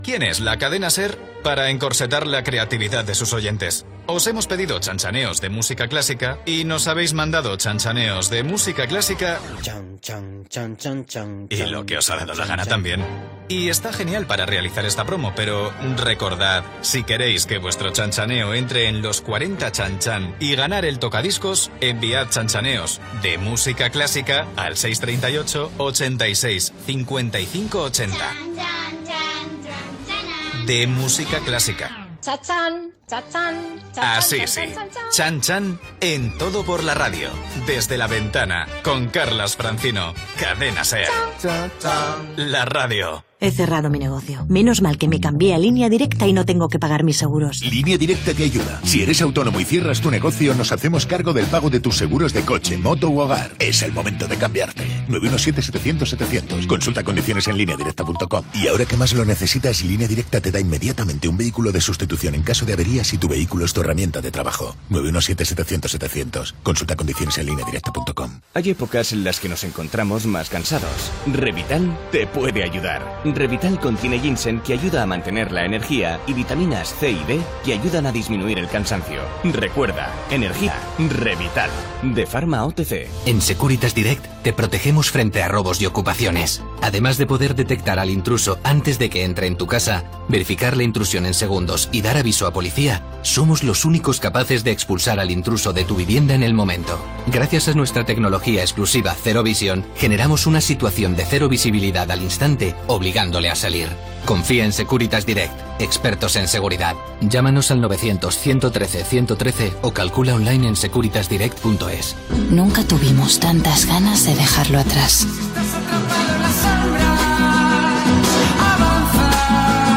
Info-entreteniment